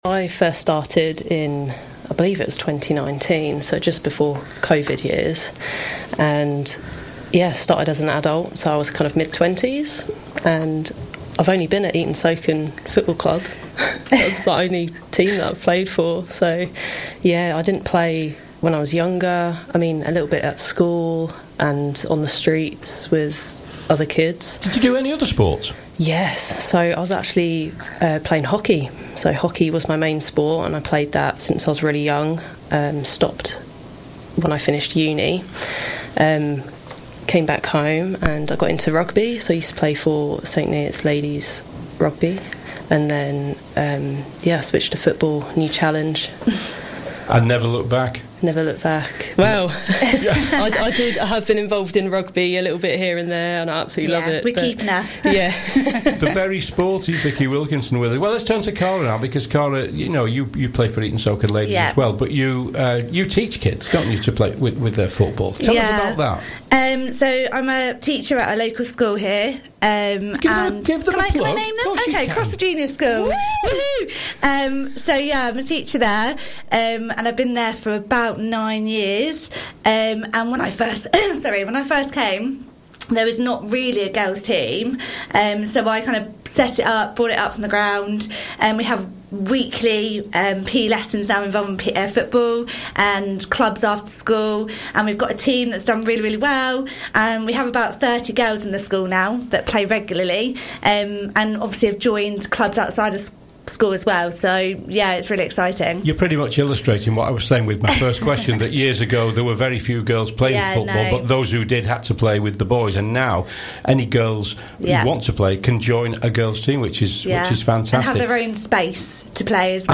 Blackcat Radio Interview